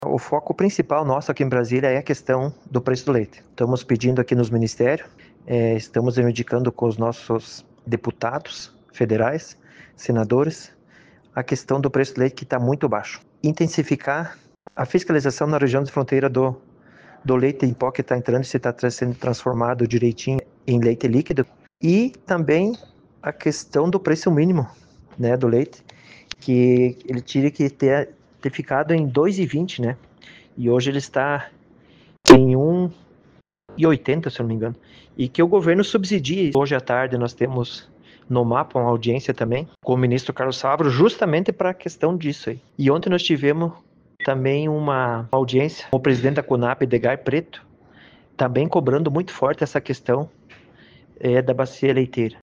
O vereador, Marciano Rubert, disse nessa manhã para a RPI que a pauta principal é a questão leiteira, visto baixo preço pago ao agricultor, dentre outras questões.